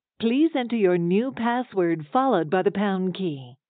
*⃣ Asterisk sound 'vm-newpassword.wav'